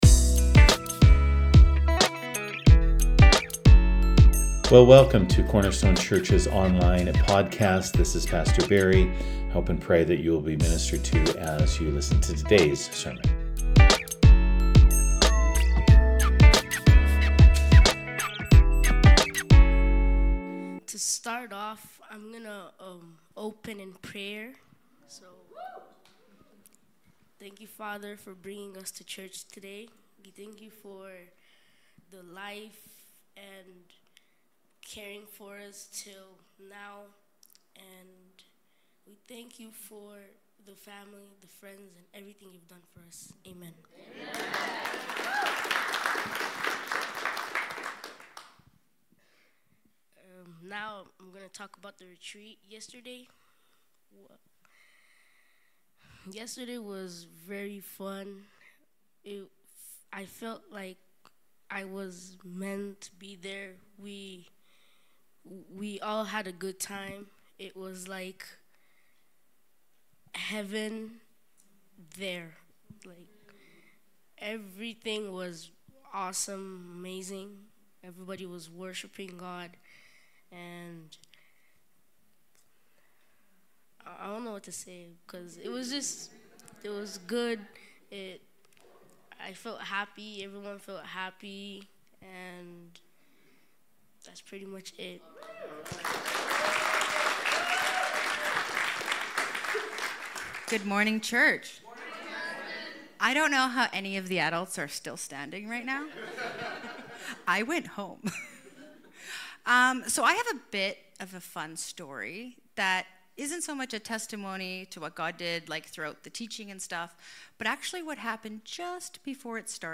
Youth Service!
In today's service, the Revival youth group shares their experiences from the weekend Change Conference.